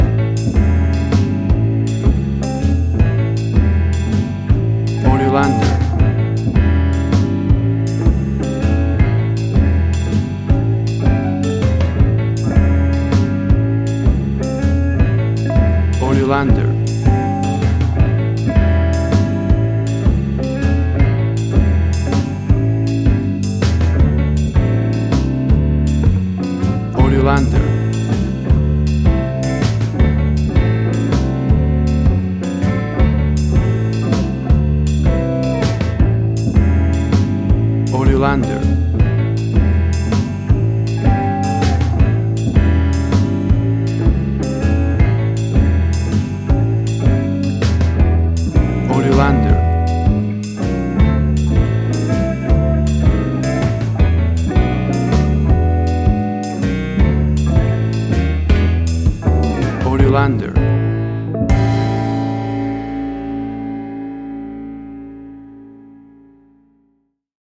WAV Sample Rate: 16-Bit stereo, 44.1 kHz
Tempo (BPM): 106